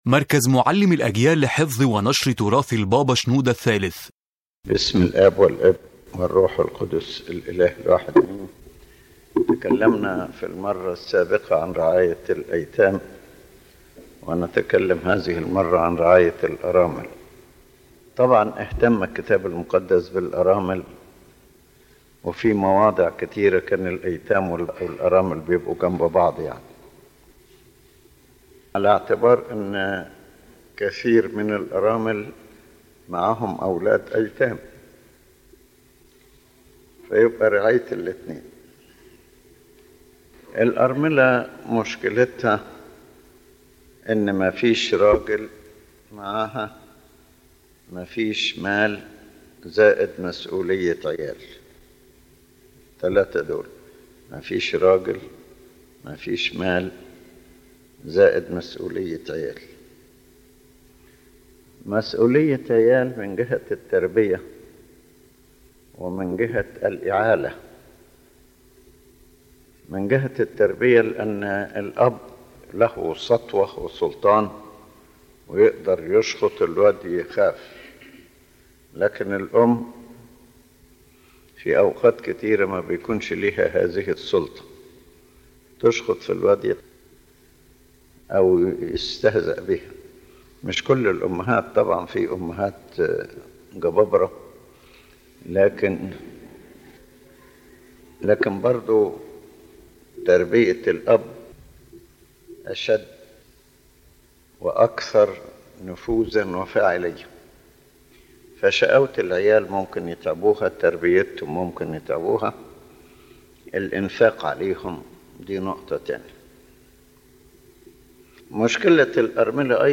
يتحدث قداسة البابا شنوده الثالث عن مكانة الأرامل في الكتاب المقدس، حيث يقترن ذكرهن كثيرًا بالأيتام، نظرًا لارتباط مشكلتهن غالبًا بوجود أولاد يحتاجون إلى رعاية وتربية وإعالة.